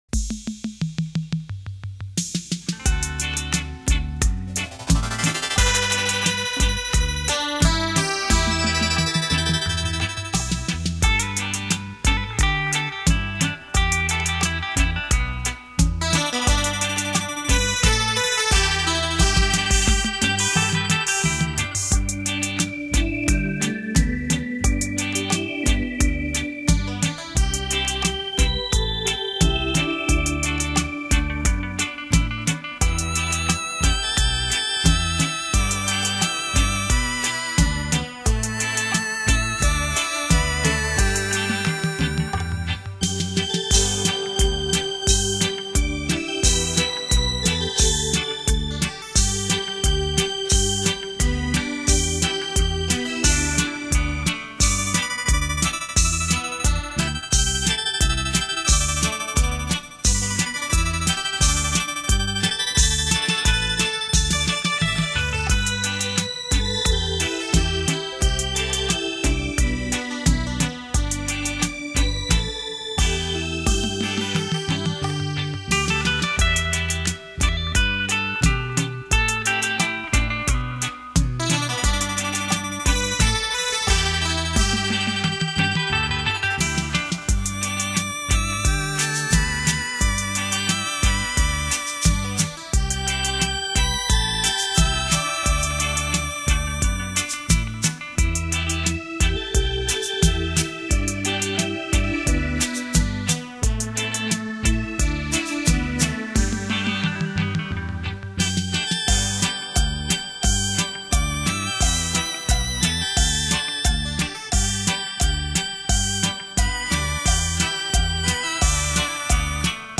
史上最强720度环绕立体声
360度超炫立体音效玩弄音乐于股掌